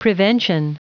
Prononciation du mot prevention en anglais (fichier audio)
Prononciation du mot : prevention